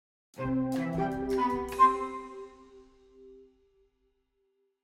Logo Sonoro